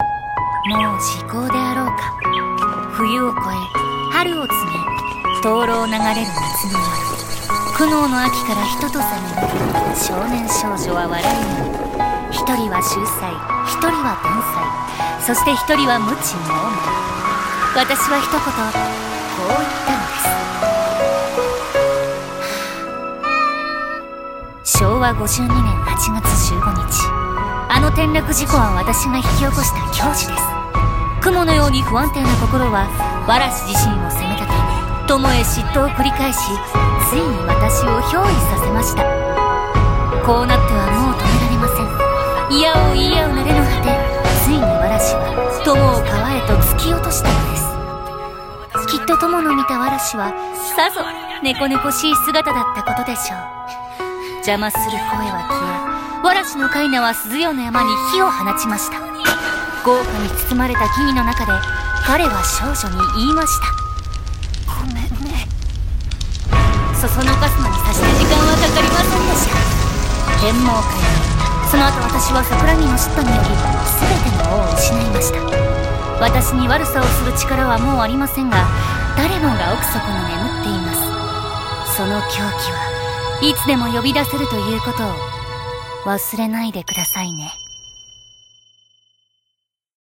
CM風声劇「猫叉逼塞独白譚」お手本